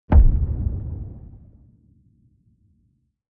wpn_giantclub_distant_003.wav